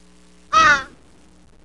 Chimpanzee Sound Effect
Download a high-quality chimpanzee sound effect.
chimpanzee.mp3